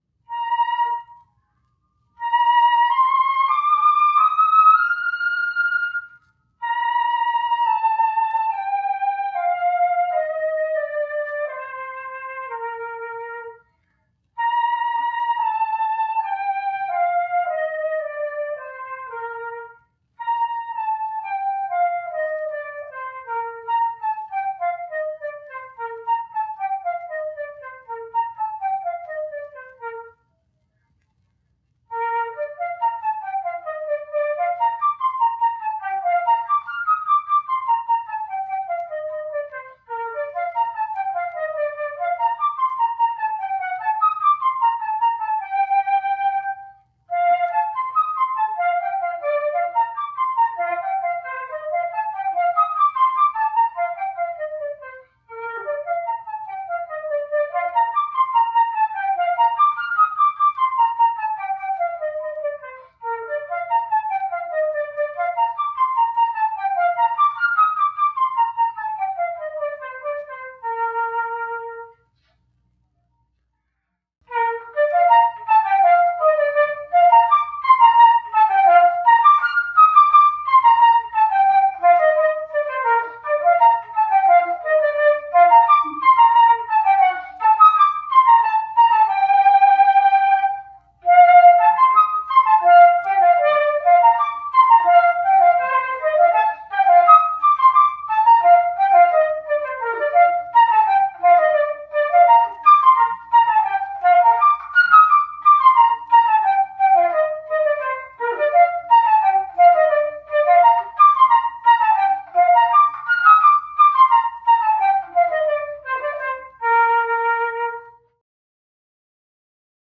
To help practice for this movement, Marcel Moyse playing 24 Little Melodic Studies No.15- I refer to this study a lot for musical, resonant staccatos.
Playing his study No. 15